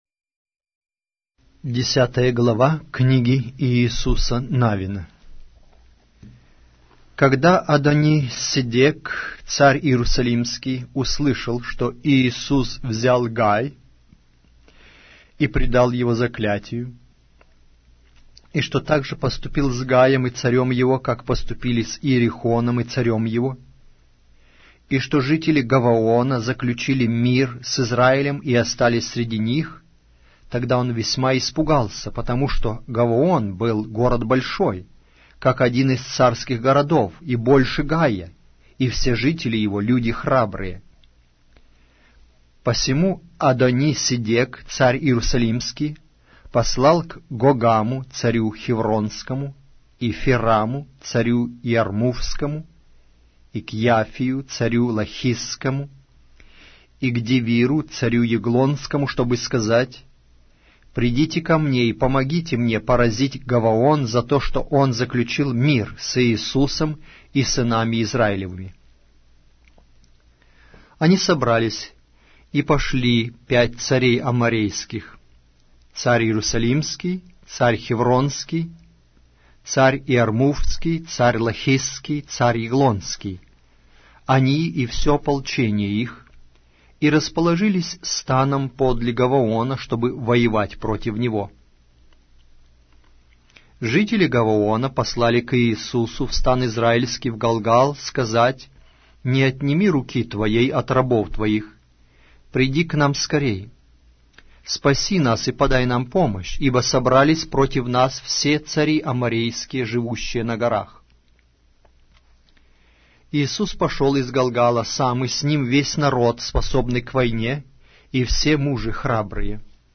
Аудиокнига: Иисус Навин